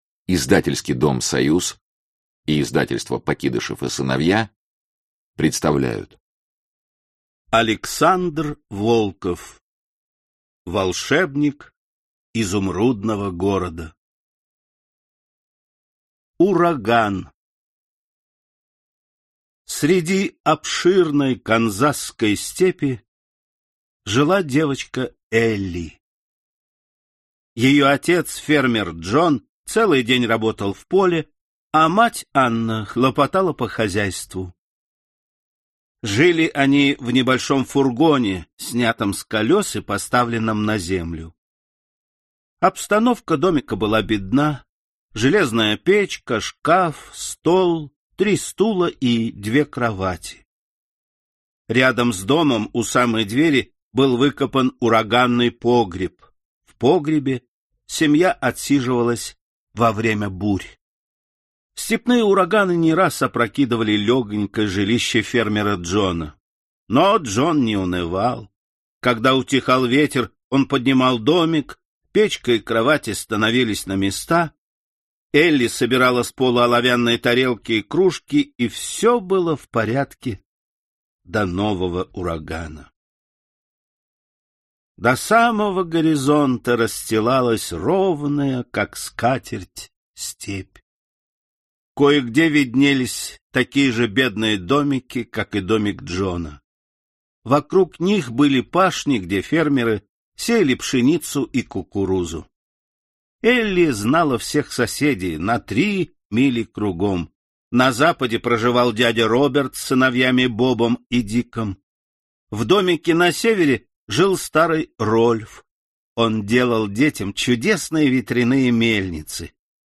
Аудиокнига Волшебник Изумрудного города | Библиотека аудиокниг